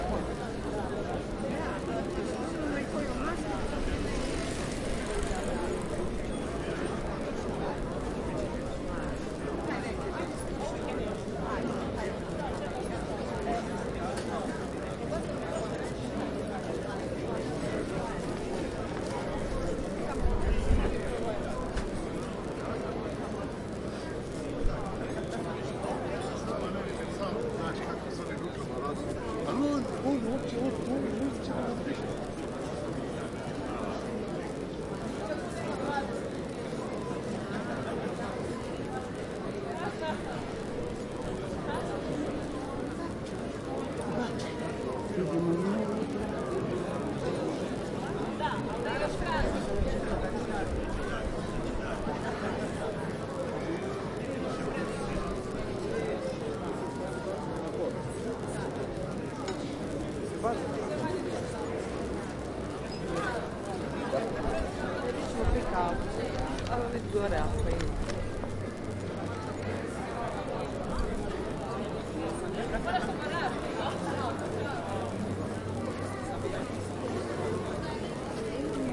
环境之声 " 庭院拍卖环境
描述：记录在院子里出售。位置就在人行道上，所以大部分的声音都是通过交通，虽然有很多的谈话和偶尔的笑声。 粗糙发动机的大卡车在0:45左右通过。 1点18分，汽车发动机通过良好的低端声音。 有人在1:35喜欢他的汉堡包。 :) 仍然在轮胎上的螺柱的汽车在2:25通过。 有人在2:35打开一个流行音乐。 摩托车于3:42经过。 有人问我4:18我是怎么回事。 :) 录制于iPhone 6 PlusZoom iQ7中端立体声麦克风120°立体声宽度
标签： 街道的噪音 音景 氛围 环境 交通 街道 氛围 现场录音 噪音 大气 城市
声道立体声